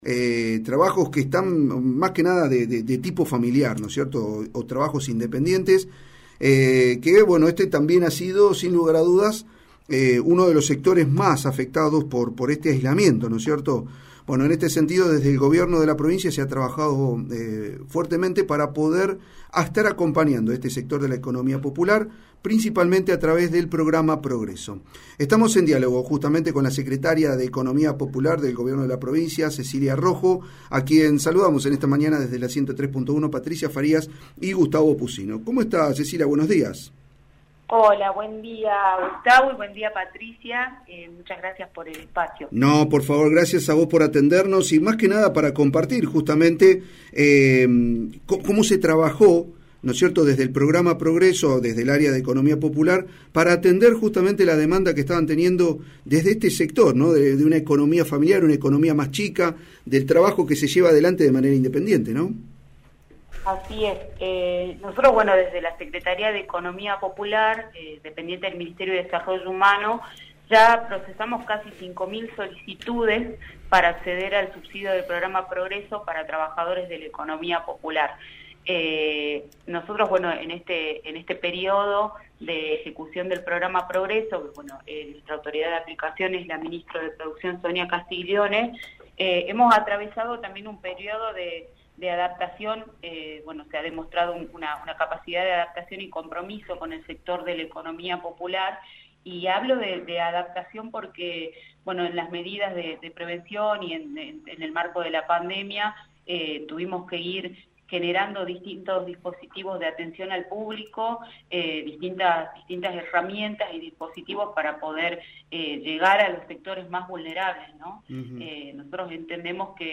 La secretaria de Economía Popular, Cecilia Rojo, habló el la 103.1 la radio pública fueguina donde brindó detalles de las acciones desarrolladas.